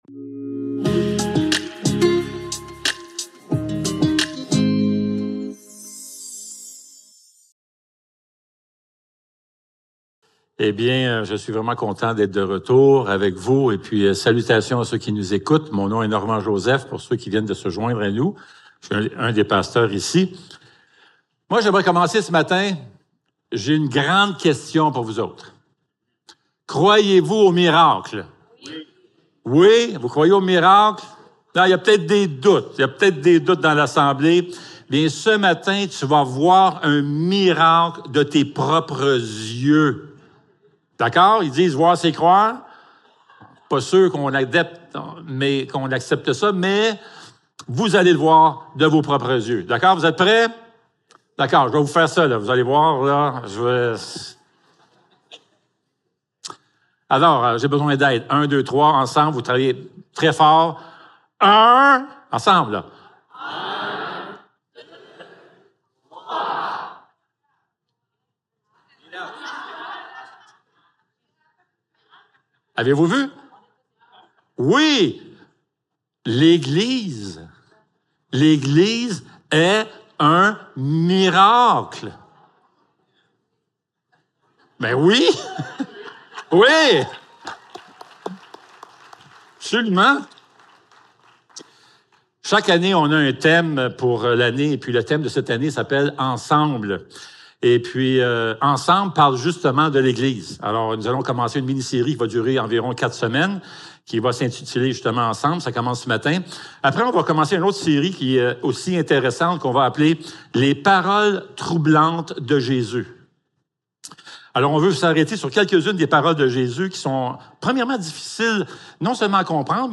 Matthieu 16.13-18 Service Type: Célébration dimanche matin Description